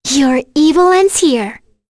Kirze-Vox_Skill6_b.wav